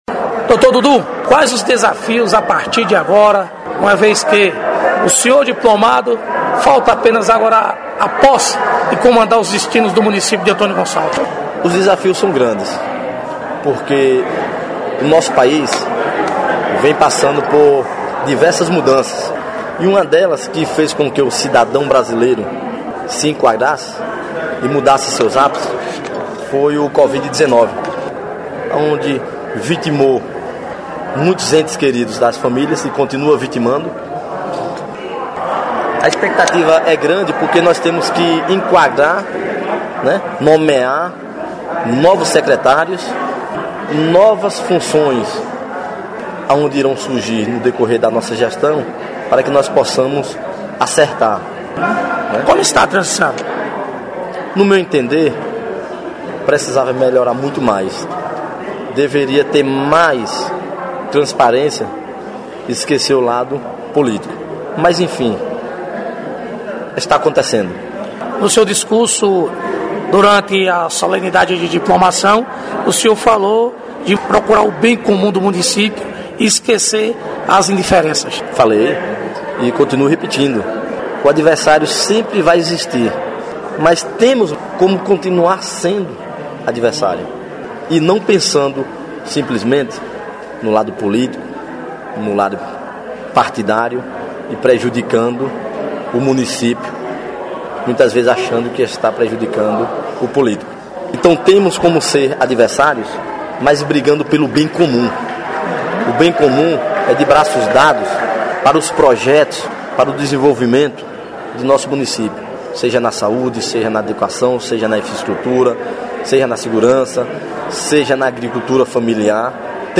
Reportagem: Diplomação em Antônio Gonçalves do prefeito eleito Dudu e vice-prefeita eleita Irene Costa